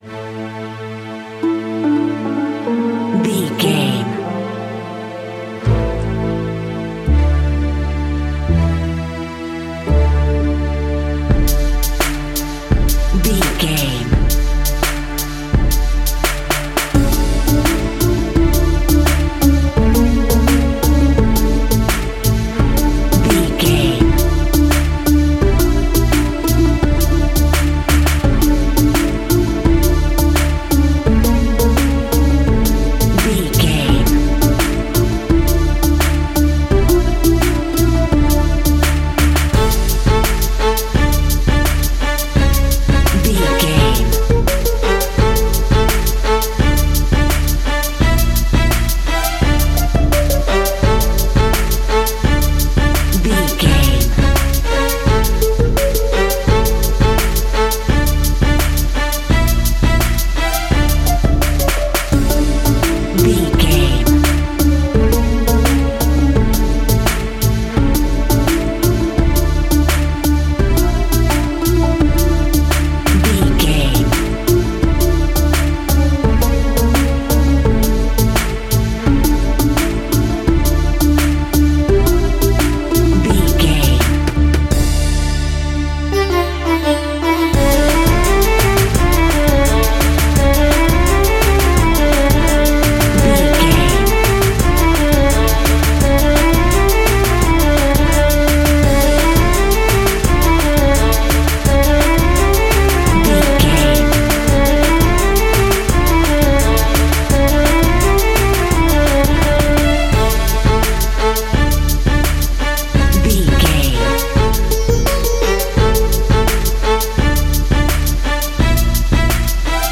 Aeolian/Minor
Slow
World Music
percussion